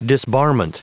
Prononciation du mot disbarment en anglais (fichier audio)
Prononciation du mot : disbarment